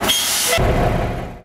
turn_off.wav